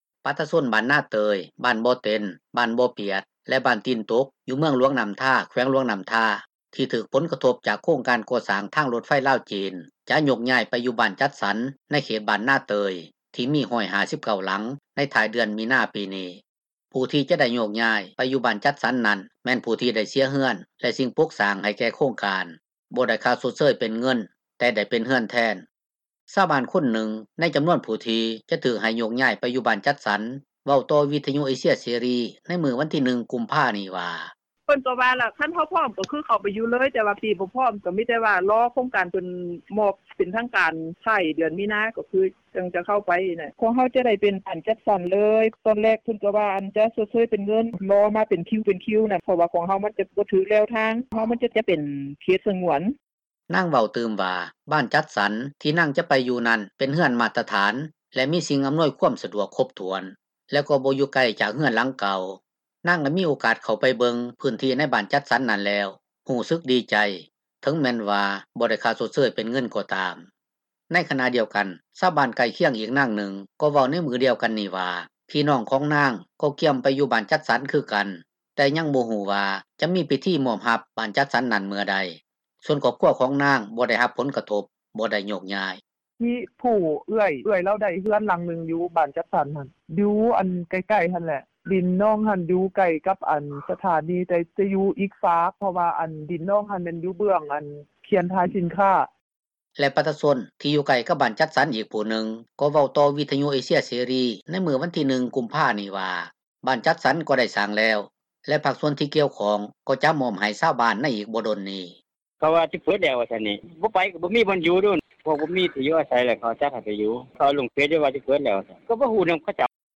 ຊາວບ້ານຄົນນຶ່ງ ໃນຈໍານວນຜູ້ທີ່ຈະຖືກ ໃຫ້ໂຍກຍ້າຍໄປຢູ່ບ້ານຈັດສັນ ເວົ້າຕໍ່ ວິທຍຸເອເຊັຽເສຣີ ໃນມື້ວັນທີ 1 ກຸມພານີ້ວ່າ:
ດັ່ງຊາວບ້ານນາງນຶ່ງເວົ້າໃນມື້ດຽວກັນນີ້ວ່າ: